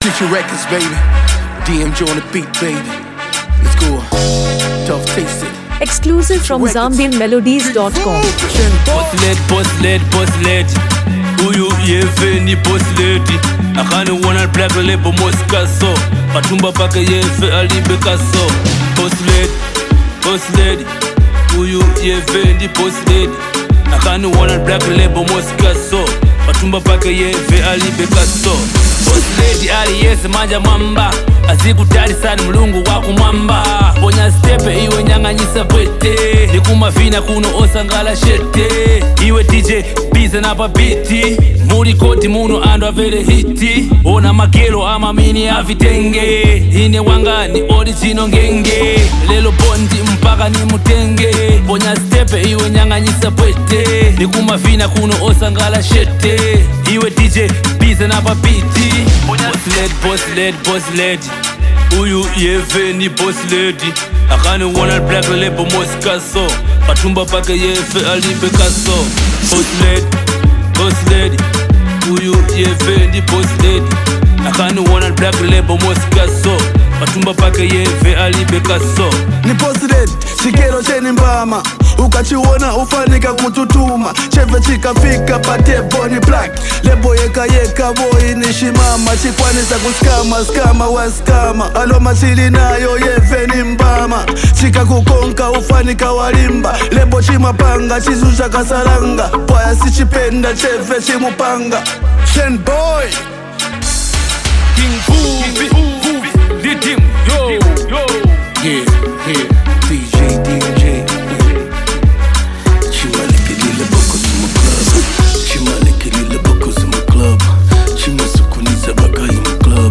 a vibrant club banger
independent women through energetic Afro-pop vibes.
Genre: Dancehall,